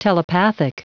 Prononciation du mot telepathic en anglais (fichier audio)
Prononciation du mot : telepathic